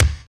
KIK GOODY04L.wav